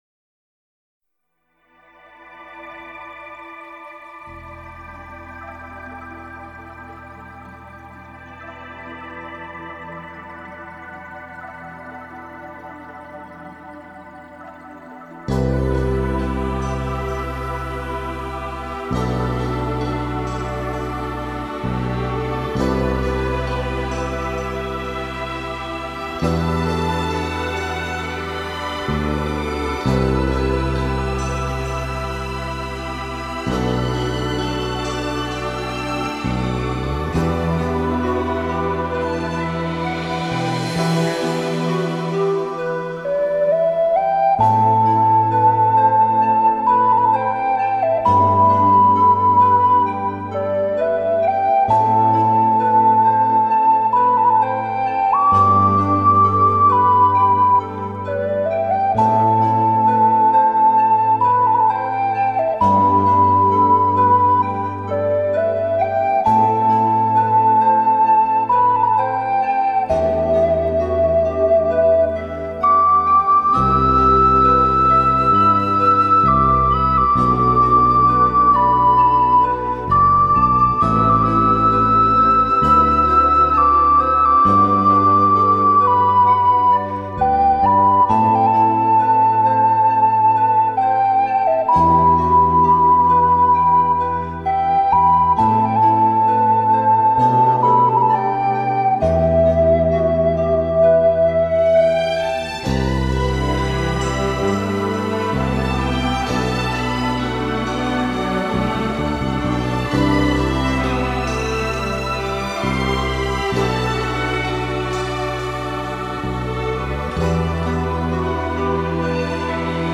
类别：新世纪音乐